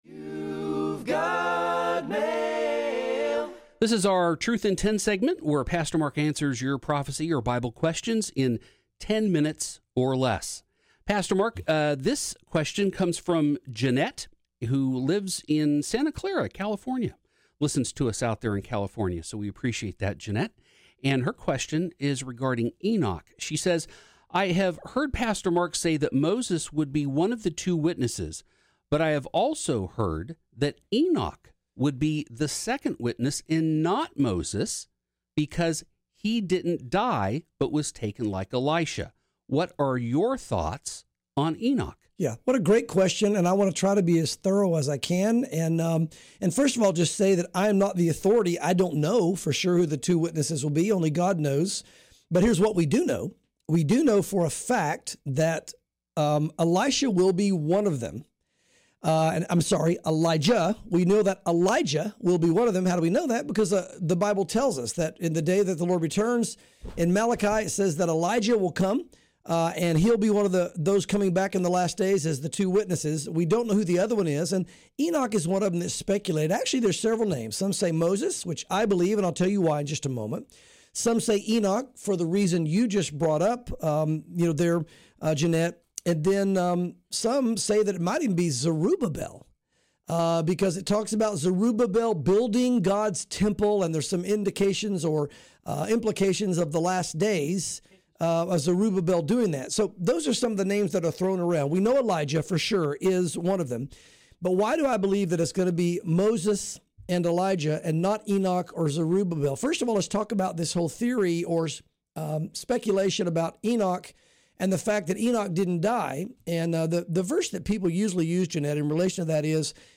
| Truth in 10 download sermon mp3 download sermon notes Welcome to Calvary Chapel Knoxville!